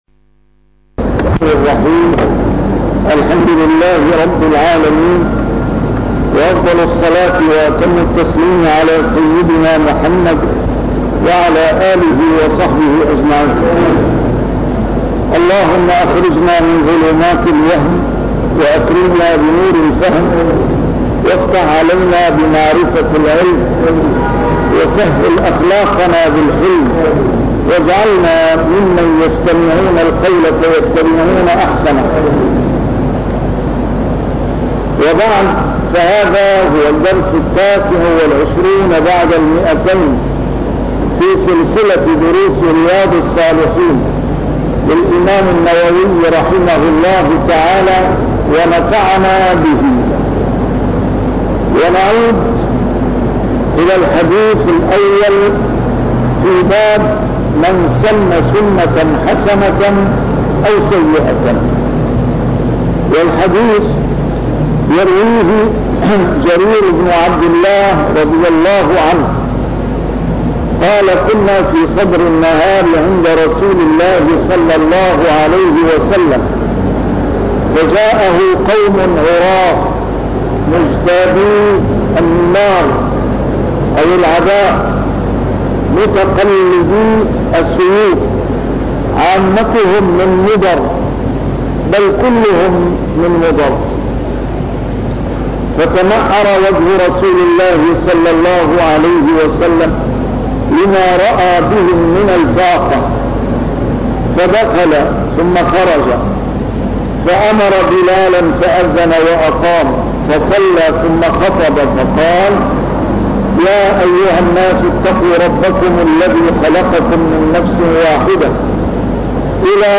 A MARTYR SCHOLAR: IMAM MUHAMMAD SAEED RAMADAN AL-BOUTI - الدروس العلمية - شرح كتاب رياض الصالحين - 229- شرح رياض الصالحين: فيمن سنَّ سنّةً